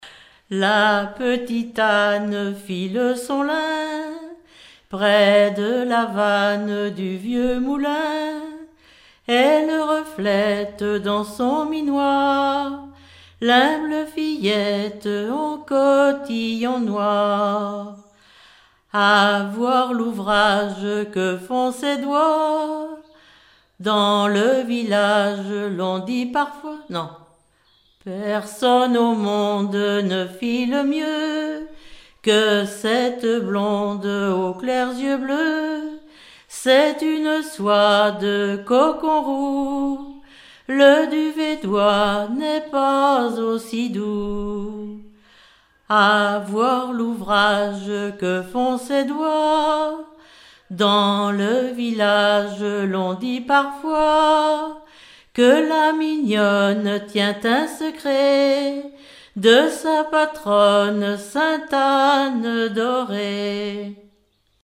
Répertoire de chansons populaires
Pièce musicale inédite